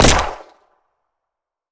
gun_pistol_shot_03.wav